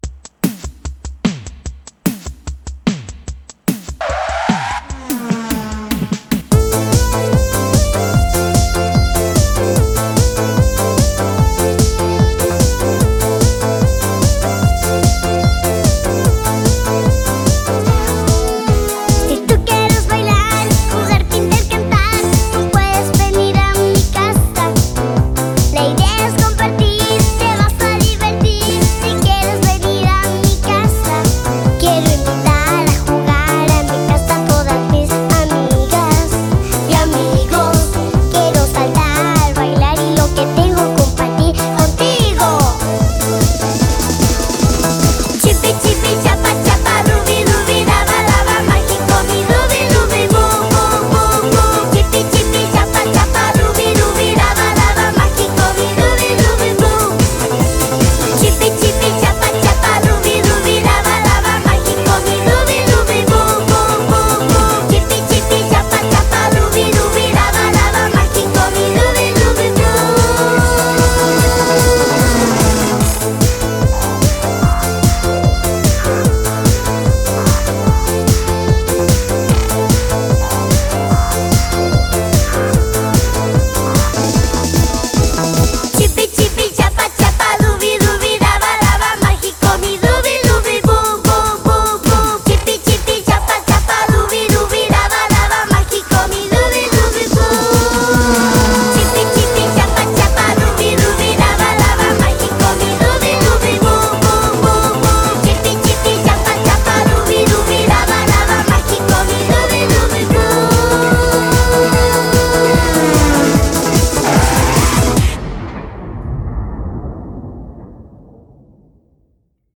BPM148
Audio QualityPerfect (High Quality)
Comentarios[KIDS POP]